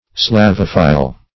Search Result for " slavophile" : The Collaborative International Dictionary of English v.0.48: Slavophil \Slav"o*phil\, Slavophile \Slav"o*phile\, n. [Slavic + Gr.